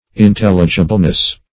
Search Result for " intelligibleness" : The Collaborative International Dictionary of English v.0.48: Intelligibleness \In*tel"li*gi*ble*ness\, n. The quality or state of being intelligible; intelligibility.